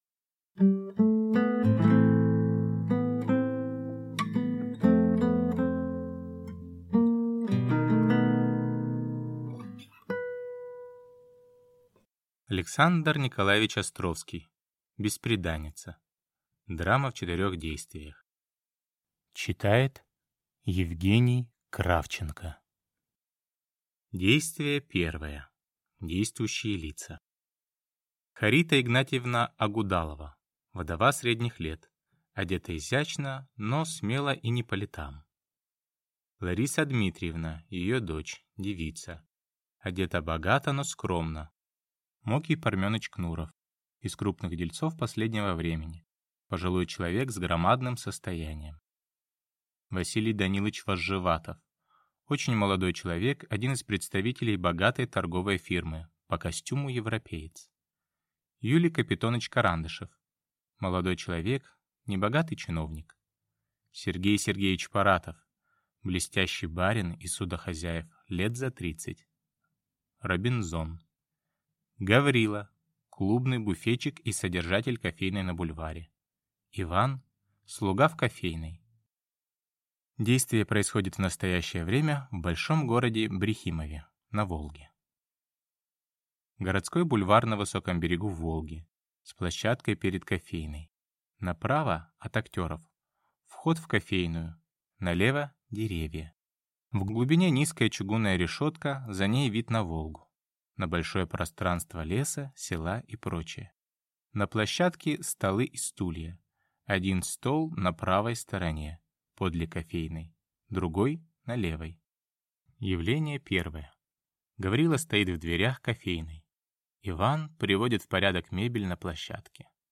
Аудиокнига Бесприданница | Библиотека аудиокниг